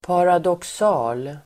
Ladda ner uttalet
paradoxal adjektiv, paradoxical Uttal: [paradåks'a:l] Böjningar: paradoxalt, paradoxala Synonymer: motsägelsefull Definition: skenbart orimlig el. motsägande Exempel: en paradoxal situation (a paradoxical situation)